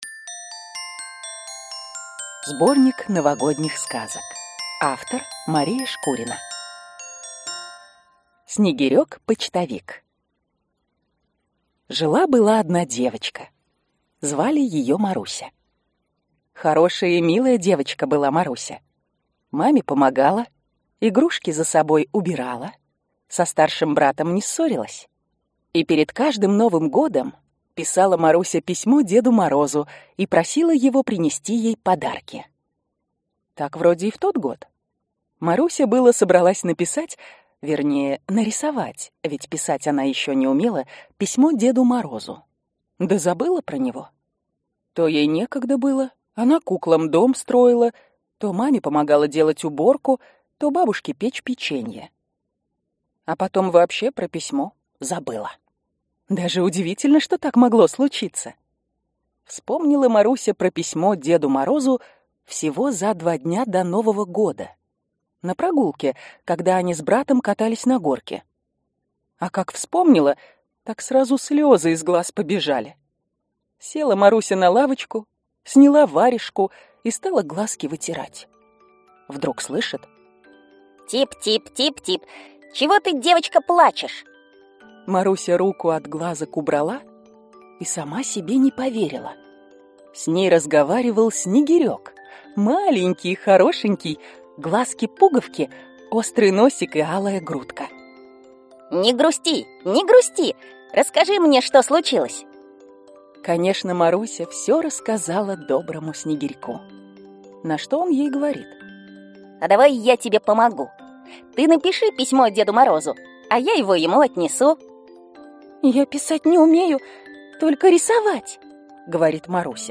Аудиокнига Сборник Новогодних сказок | Библиотека аудиокниг